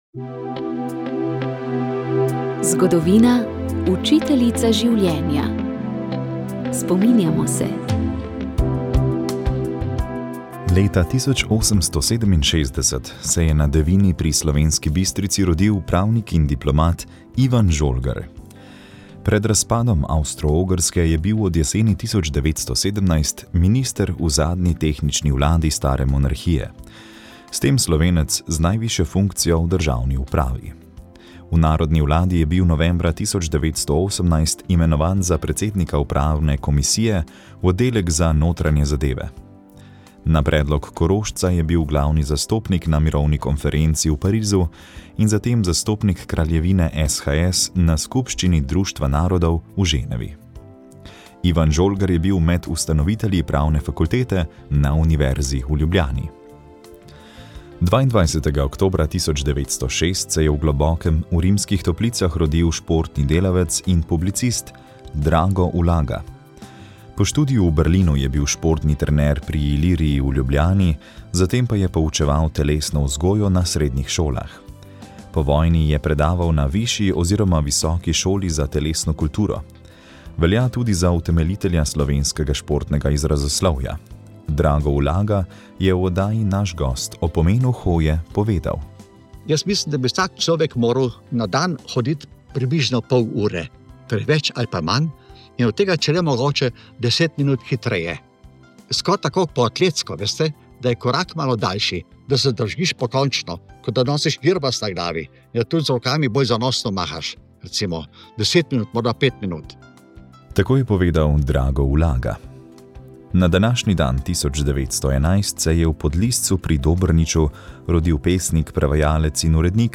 Radijska kateheza